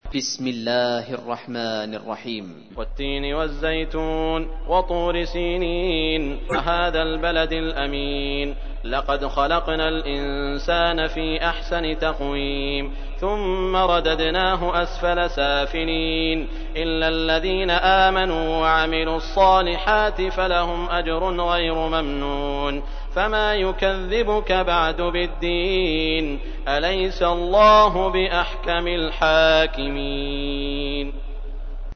تحميل : 95. سورة التين / القارئ سعود الشريم / القرآن الكريم / موقع يا حسين